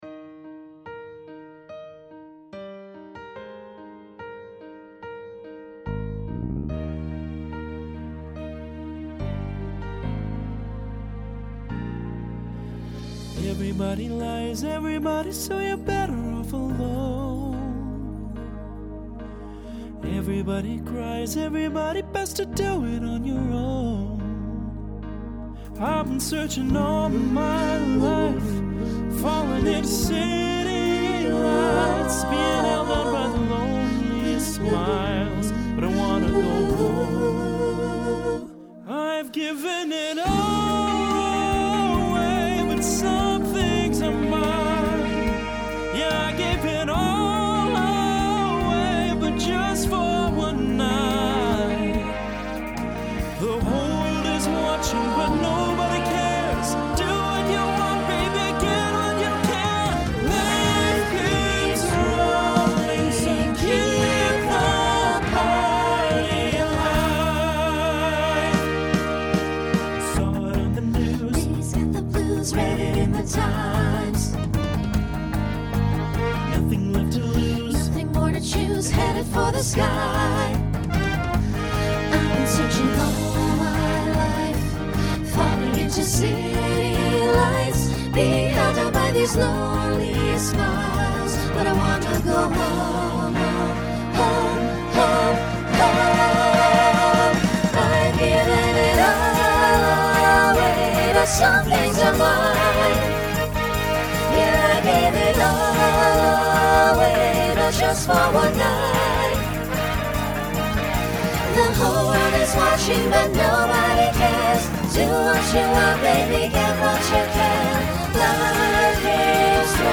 Genre Rock Instrumental combo
Solo Feature Voicing SATB